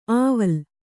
♪ āval